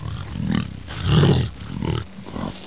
1 channel
REX-WALK.mp3